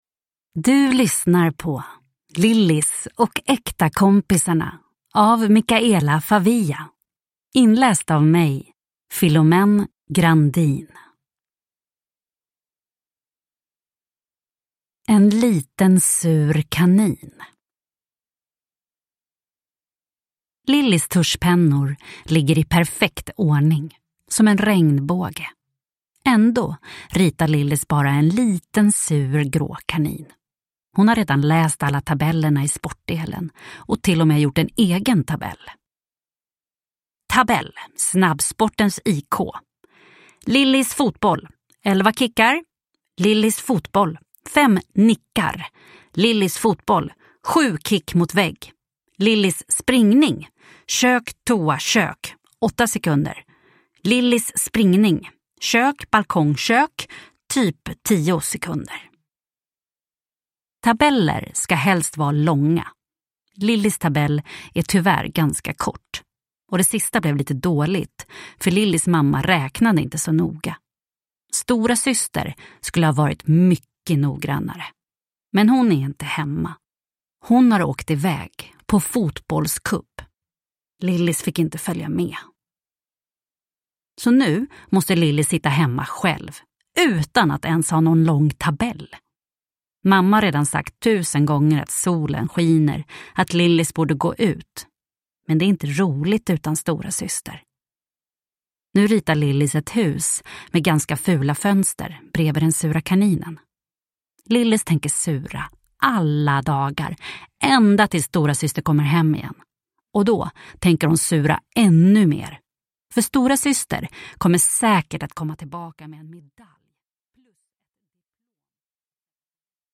Lillis och äkta kompisarna – Ljudbok – Laddas ner